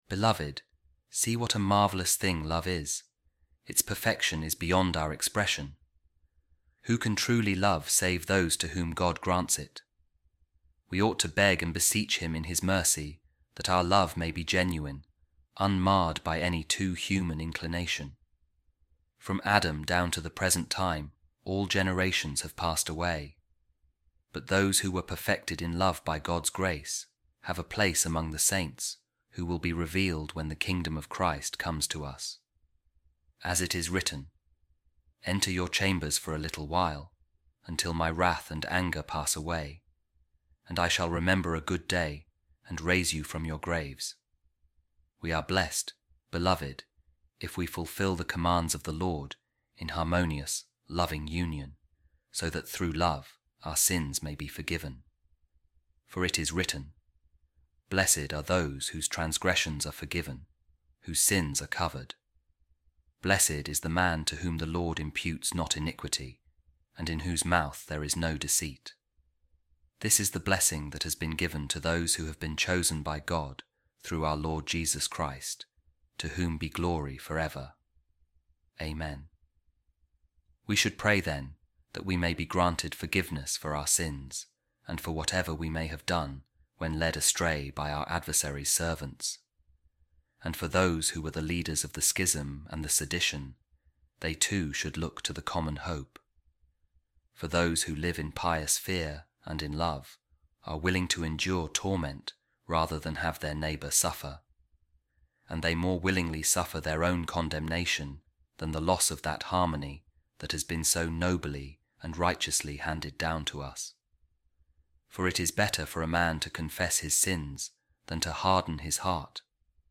A Reading From The Letter Of Pope Saint Clement I To The Corinthians | Keep God’s Commandments In Love